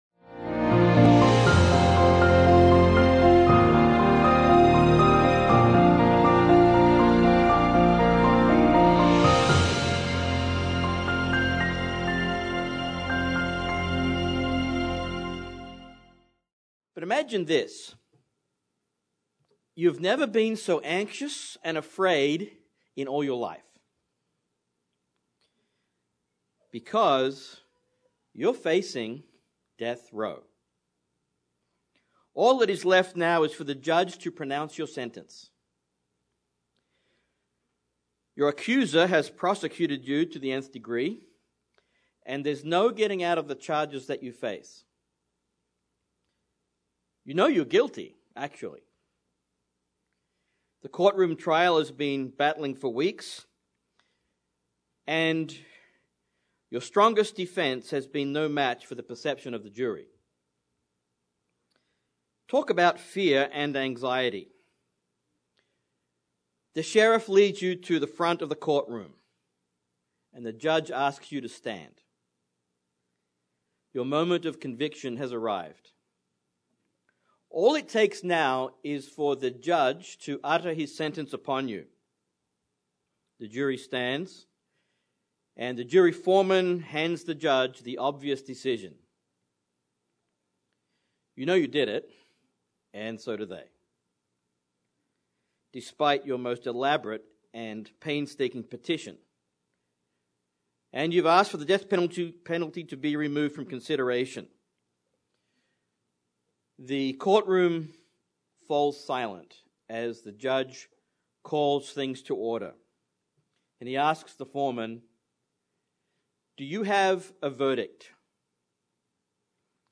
The promise of forgiveness should compel us to confess our sins to God. This sermon examines Psalm 32 and Exodus 34 to see just how deep God's mercy and forgiveness is for those who seek Him.